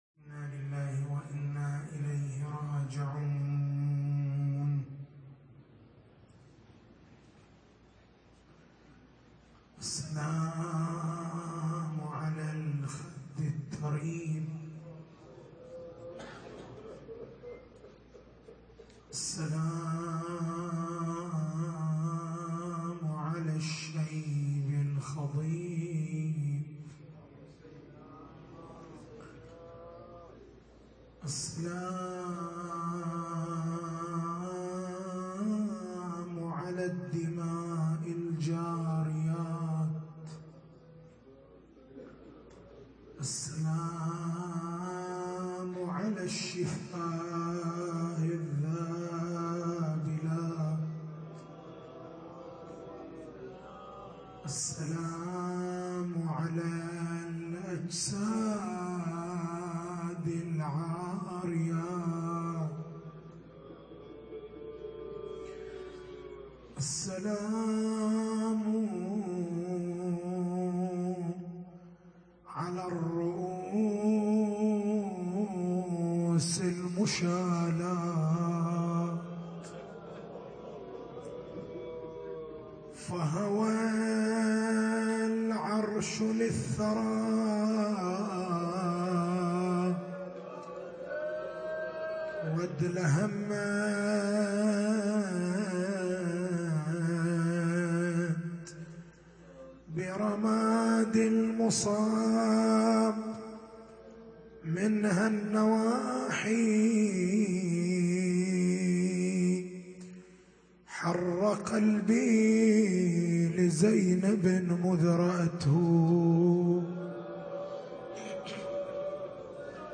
مجلس ليلة 11 محرم 1437هـ